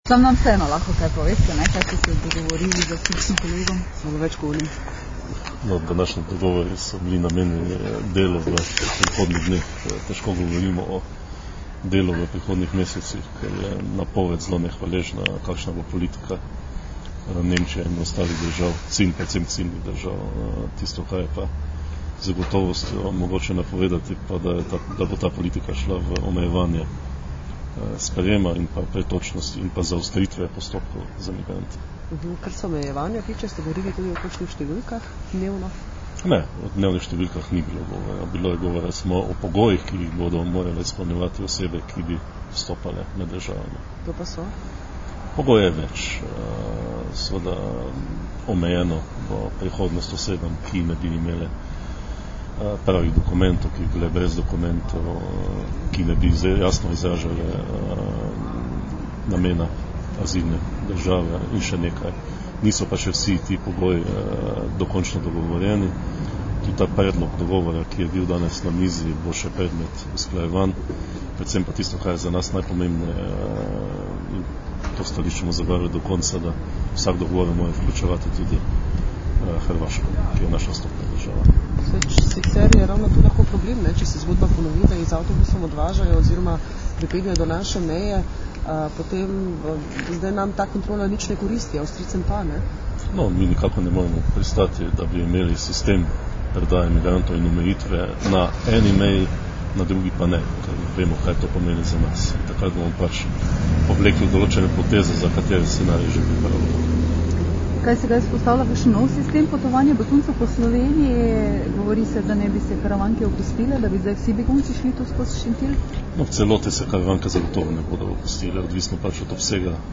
Zvočni posnetek izjave generalnega direktorja policije Marjana Fanka (mp3)